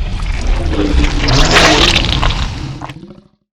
Index of /client_files/Data/sound/monster/dx1/